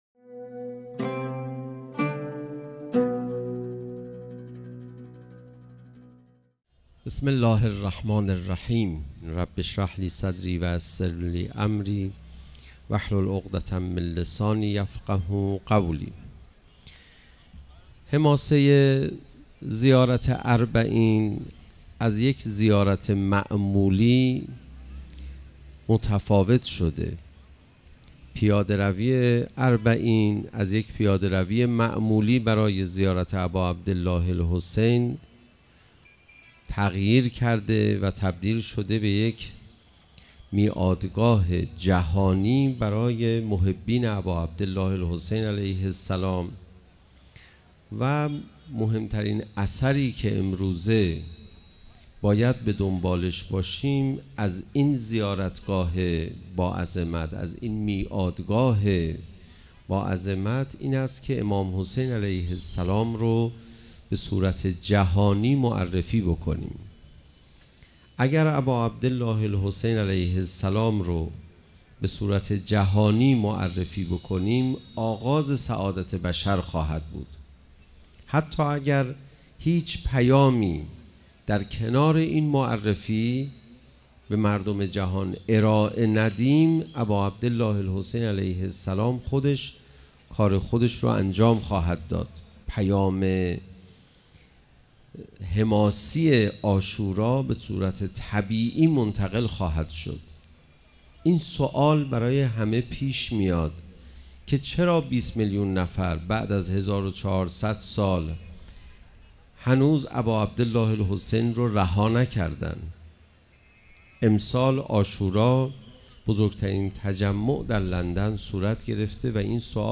سخنرانی | مهمترین اثر پیاده‌روی اربعین که باید به آن توجه کرد
سخنرانی حجت الاسلام علیرضا پناهیان - نجف - ثوره العشرین - روز اول پیاده روی اربعین 97 - هیأت شباب الامام الخمینی(ره)